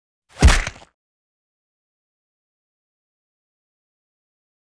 赤手空拳击中肉体－高频4-YS070524.wav
通用动作/01人物/03武术动作类/空拳打斗/赤手空拳击中肉体－高频4-YS070524.wav
• 声道 立體聲 (2ch)